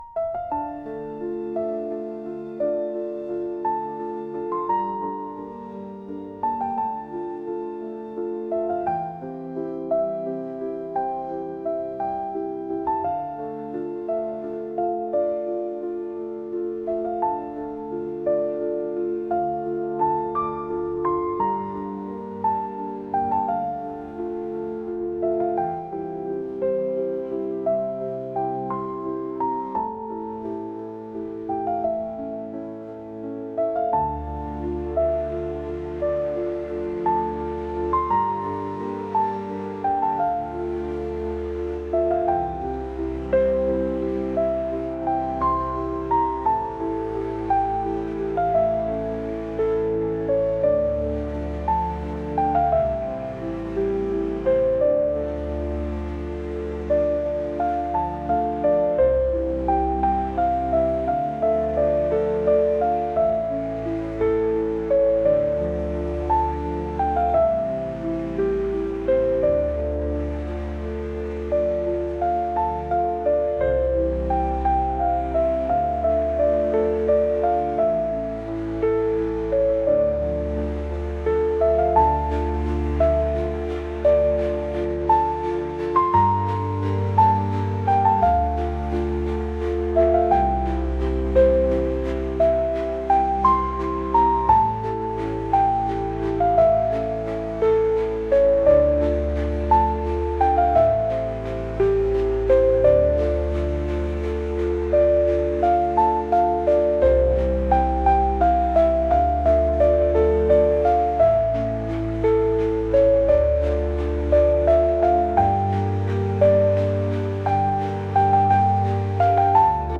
classical | cinematic | ambient